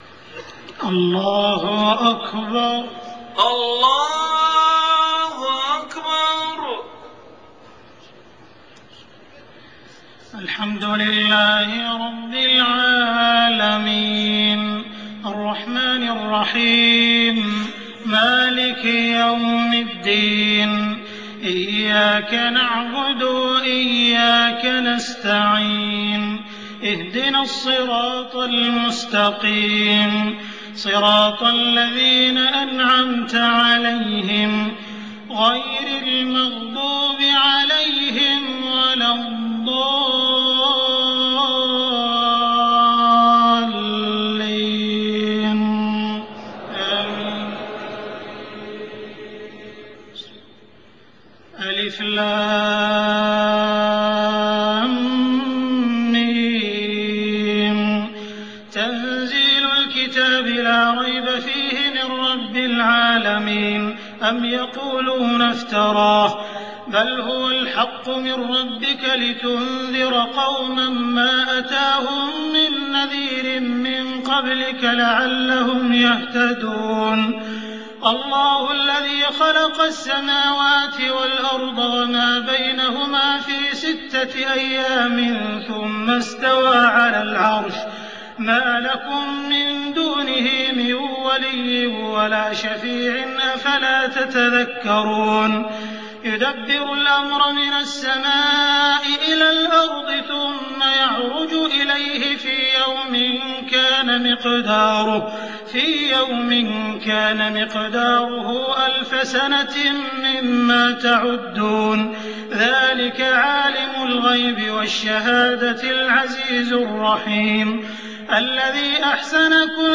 صلاة الفجر سورتي السجدة و الإنسان > 1424 🕋 > الفروض - تلاوات الحرمين